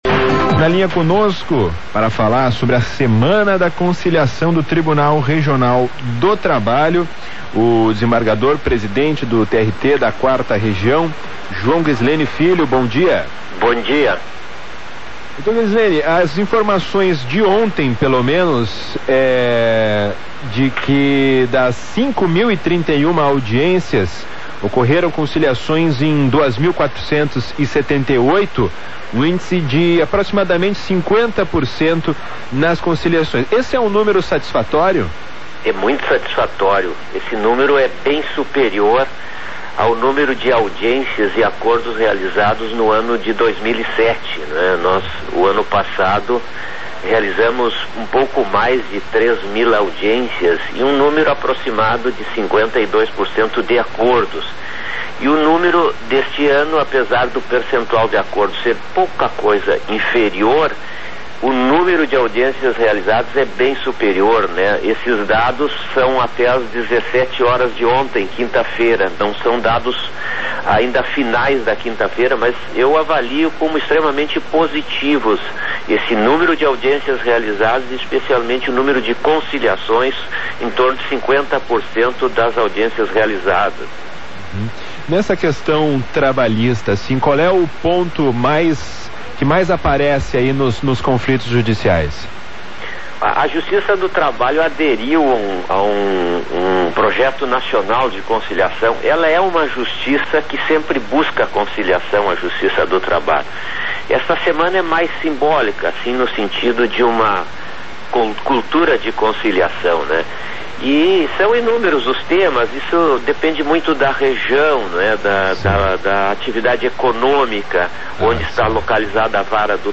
Clique no ícone do alto-falante, à direita do título, para acessar a entrevista (12min34s) concedida hoje (5) pelo Presidente do TRT-RS, Desembargador João Ghisleni Filho, ao programa CBN Porto Alegre, da Rádio CBN, sobre a Semana da Conciliação.